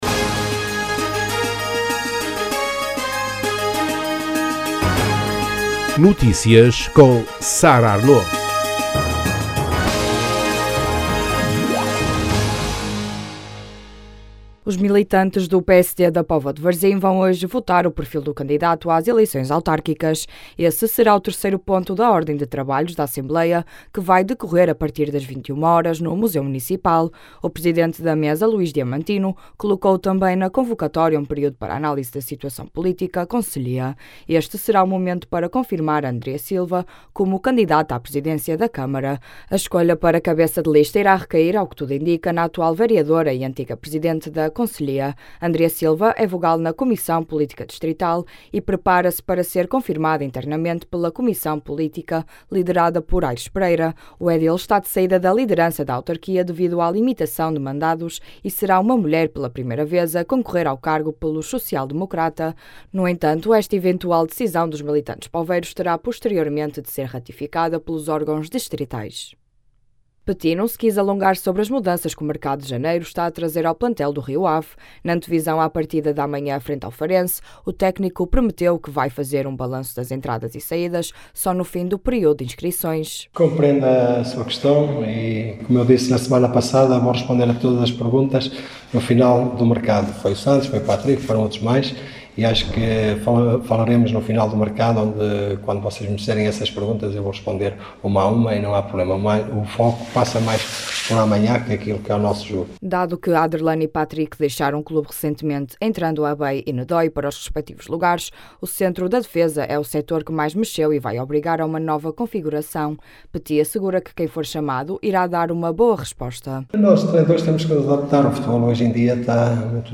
Na antevisão à partida de amanhã frente ao Farense, o técnico prometeu que vai fazer um balanço das entradas e saídas só no fim do período de inscrições.